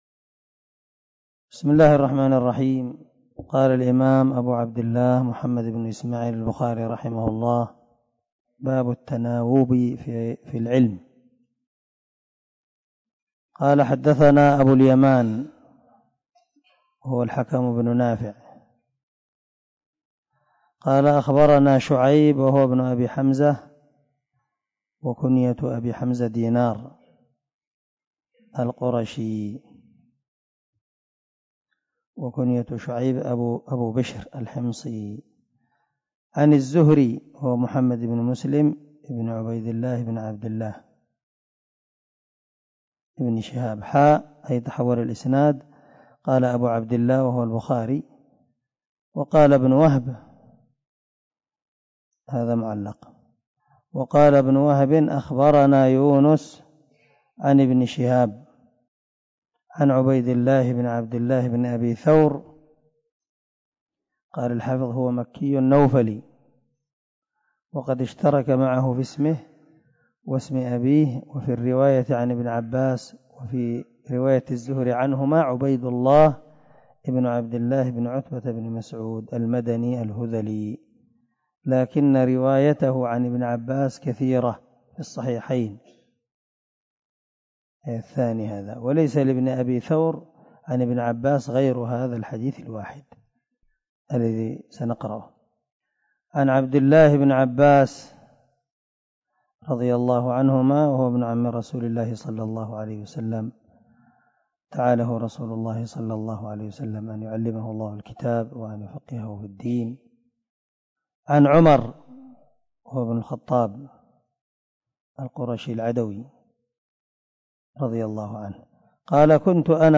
087الدرس 32 من شرح كتاب العلم حديث رقم ( 89 ) من صحيح البخاري